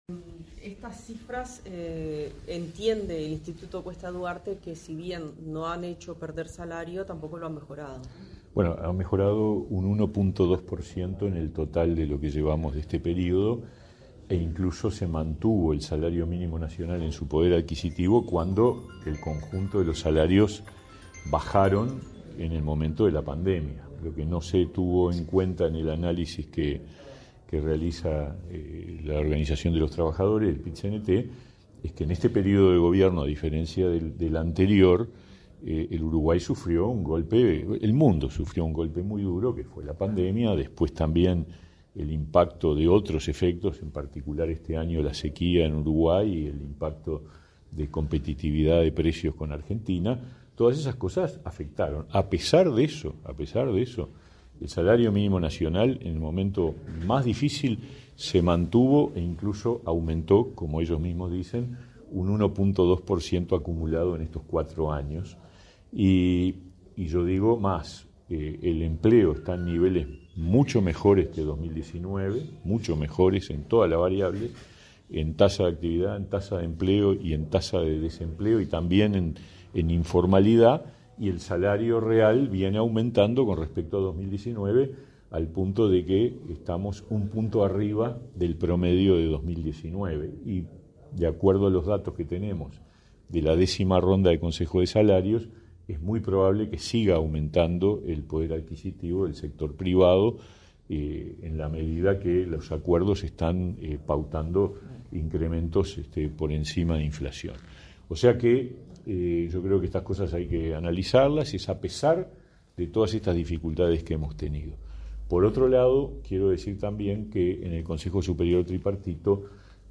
Declaraciones a la prensa del ministro de Trabajo y Seguridad Social, Pablo Mieres